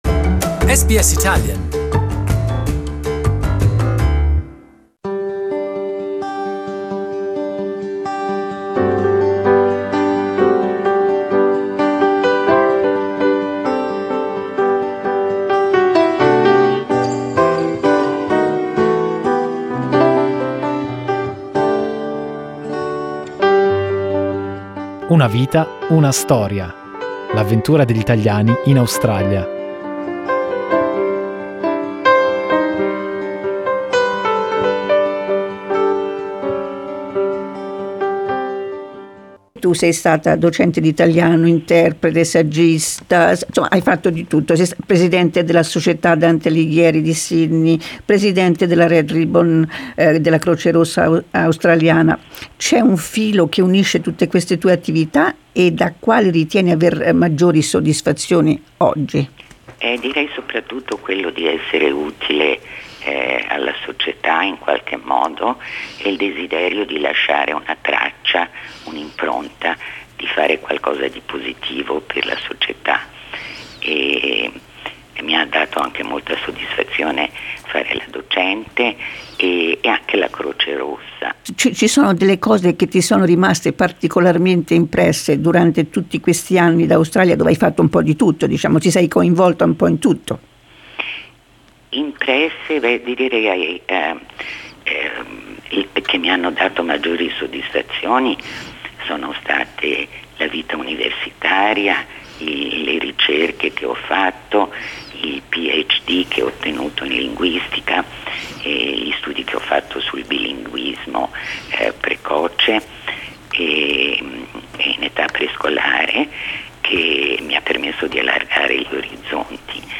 First part of the interview